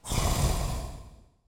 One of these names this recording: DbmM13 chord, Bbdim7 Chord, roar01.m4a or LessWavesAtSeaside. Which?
roar01.m4a